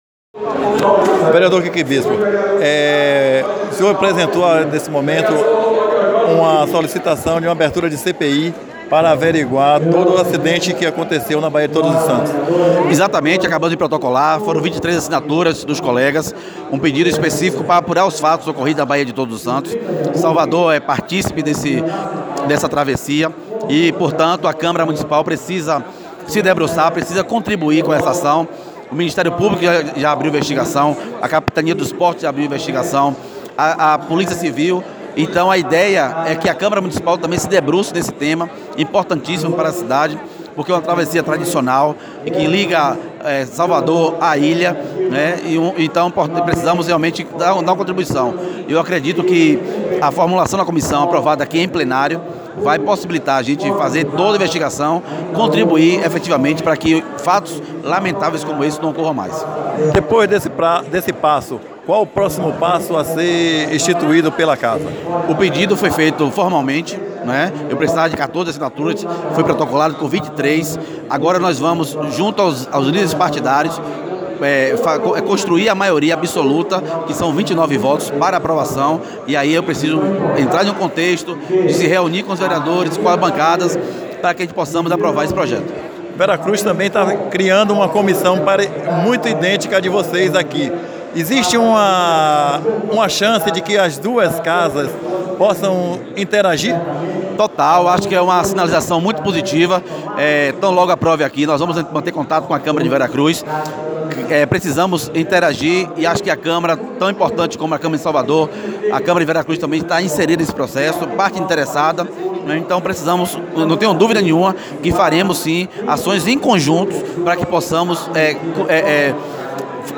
Ouça a entrevista com o vereador: